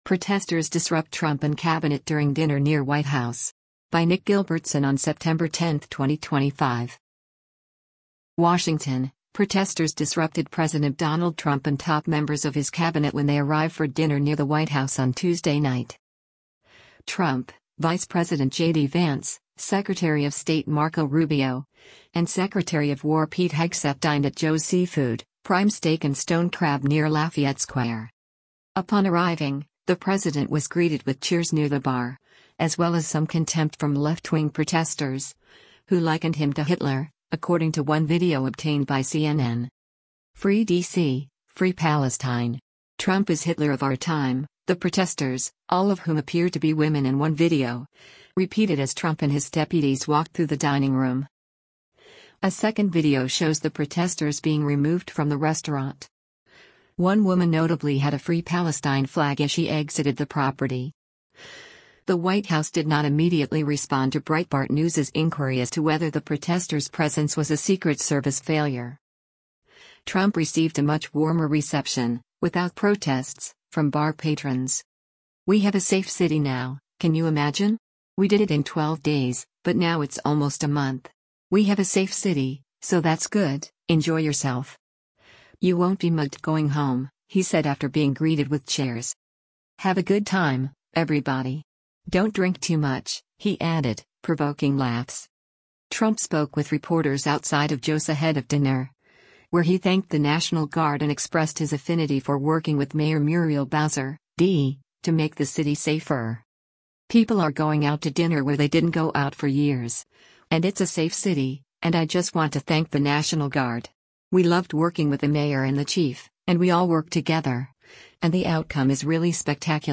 Upon arriving, the president was greeted with cheers near the bar, as well as some contempt from left-wing protesters, who likened him to Hitler, according to one video obtained by CNN.
“Free D.C., free Palestine. Trump is Hitler of our time,” the protesters, all of whom appeared to be women in one video, repeated as Trump and his deputies walked through the dining room.